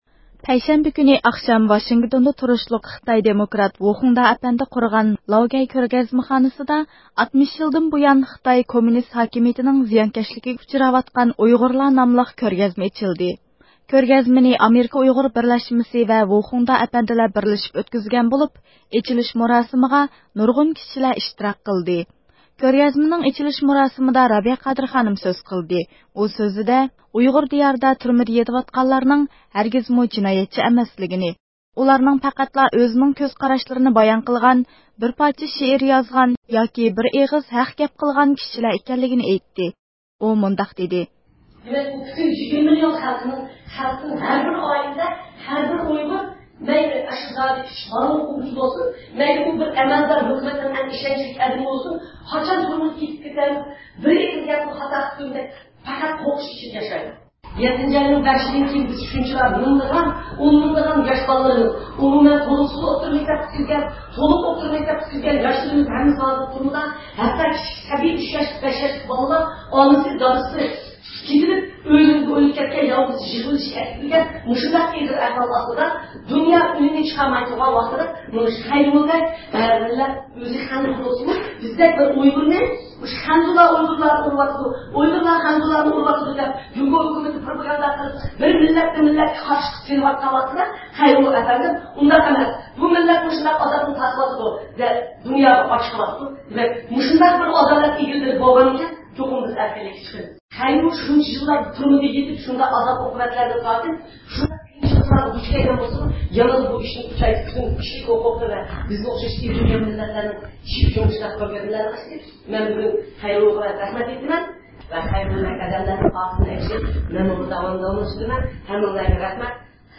رابىيە قادىر خانىمنىڭ سۆزى ئاياقلاشقاندىن كېيىن، لاۋگەي فوندى جەمئىيىتىنىڭ باشلىقى ۋە بۇ قېتىملىق كۆرگەزمىنىڭ ئۇيۇشتۇرغۇچىلىرىدىن بىرى بولغان ۋۇ خۇڭدا ئەپەندى بىلەن سۆھبەت ئېلىپ باردۇق.
بىز كۆرگەزمە جەريانىدا كۆرگەزمىنى زىيارەت قىلىۋاتقان بىر ئامېرىكىلىق خانىمغىمۇ مىكرافونىمىزنى ئۇزاتتۇق.